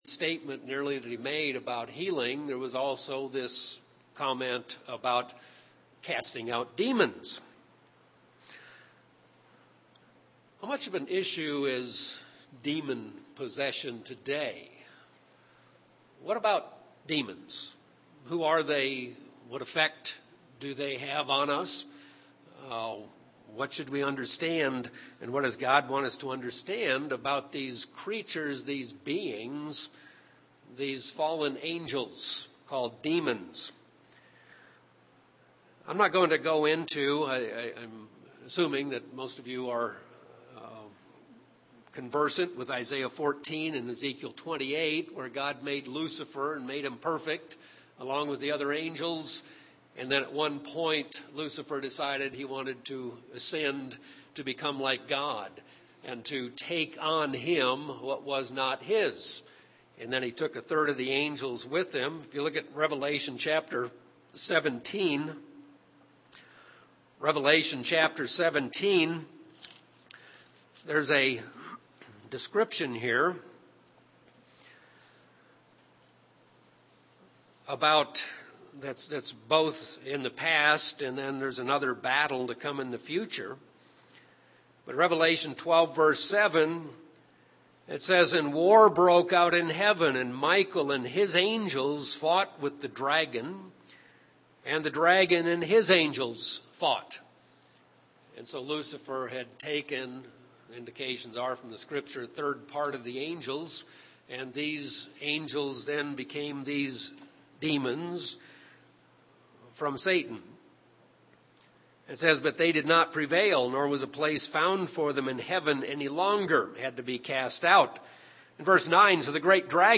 Given in Albuquerque, NM Phoenix East, AZ
UCG Sermon Studying the bible?